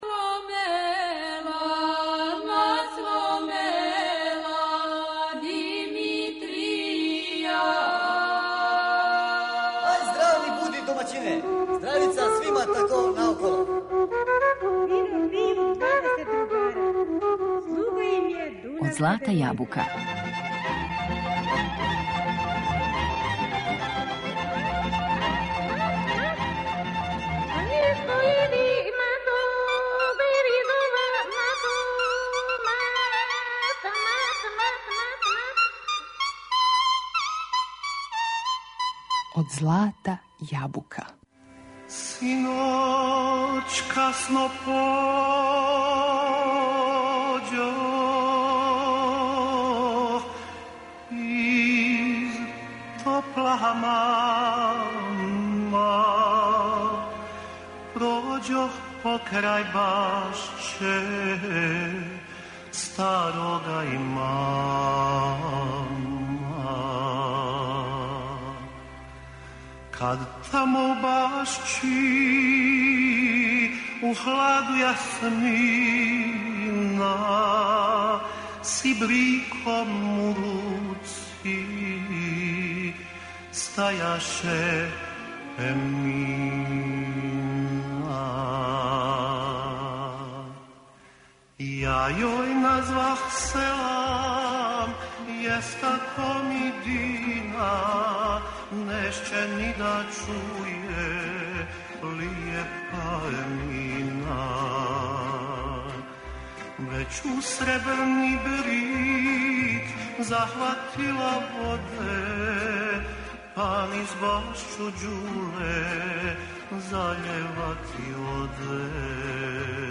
музички портрет